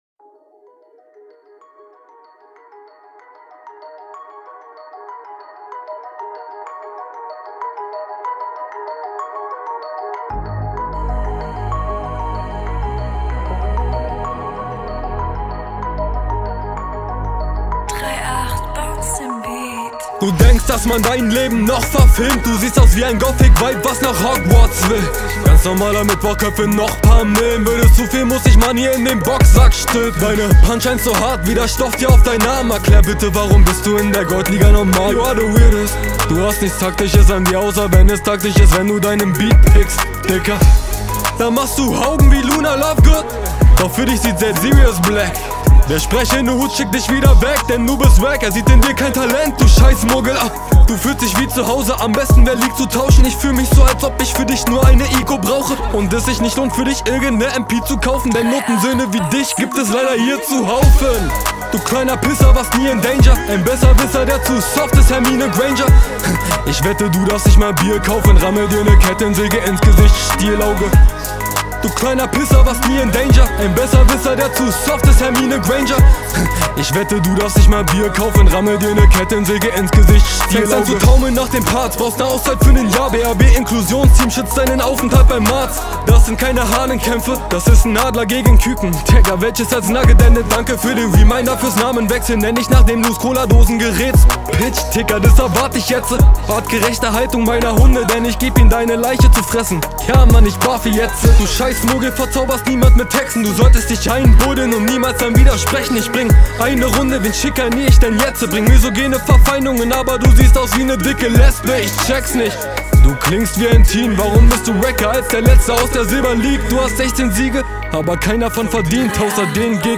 Battle Rap Bunker
Niederklassiges Battle